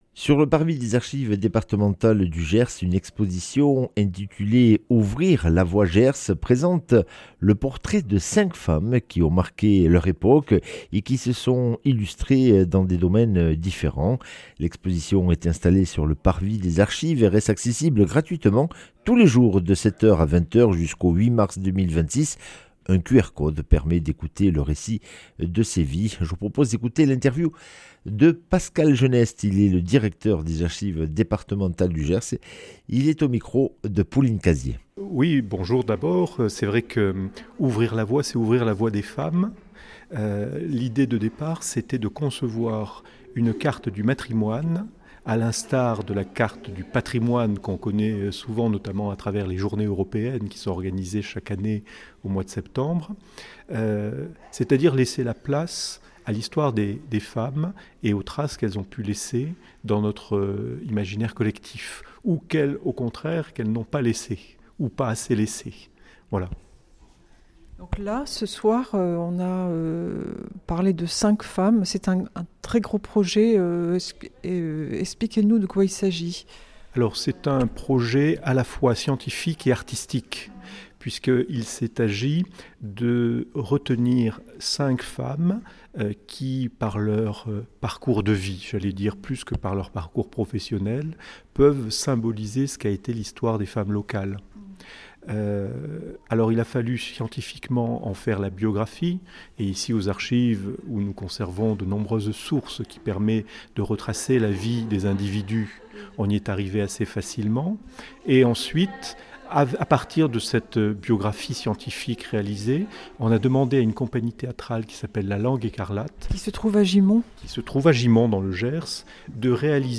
mercredi 22 octobre 2025 Interview et reportage Durée 10 min